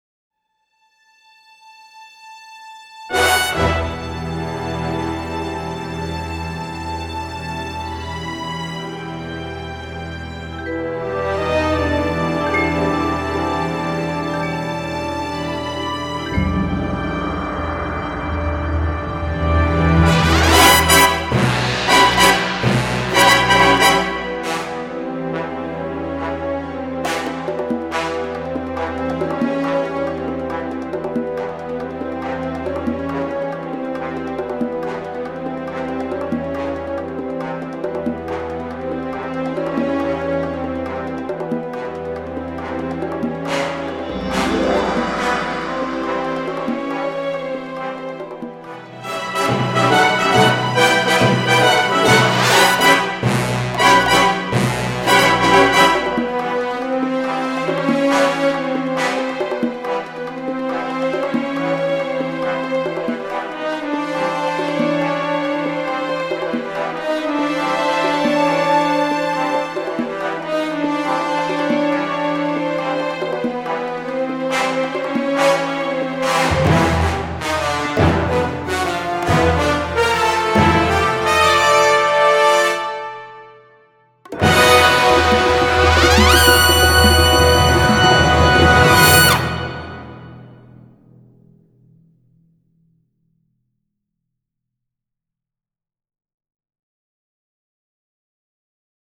Click Track Layer